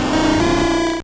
Cri de Noarfang dans Pokémon Or et Argent.